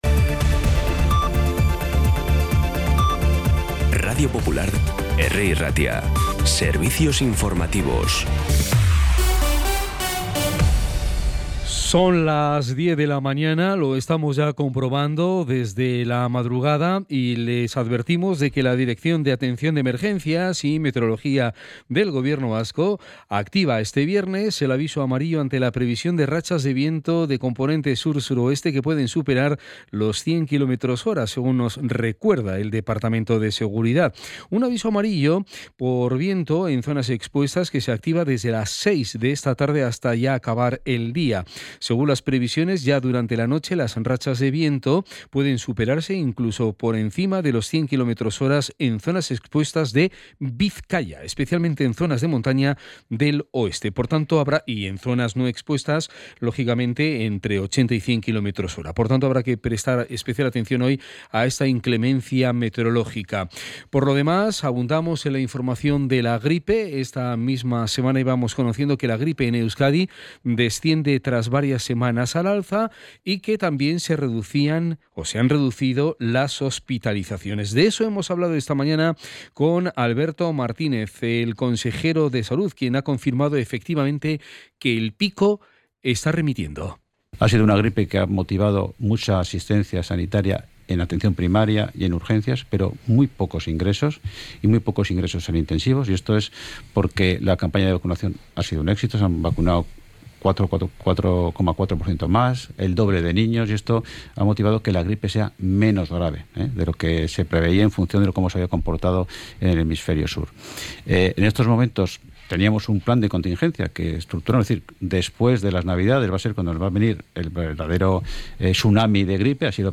Las noticias de Bilbao y Bizkaia del 24 de enero a las 10
Podcast Informativos
Los titulares actualizados con las voces del día. Bilbao, Bizkaia, comarcas, política, sociedad, cultura, sucesos, información de servicio público.